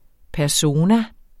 Udtale [ pæɐ̯ˈsoːna ]